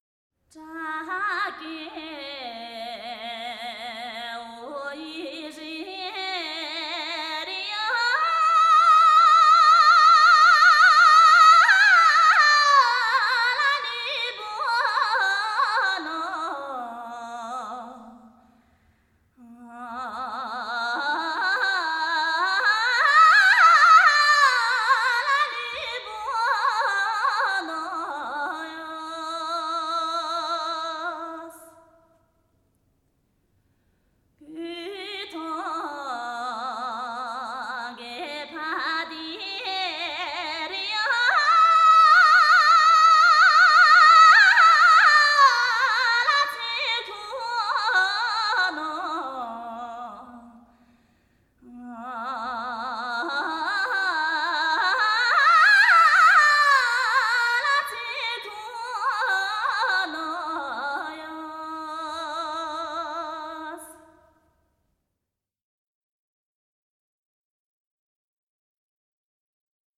山歌    1:06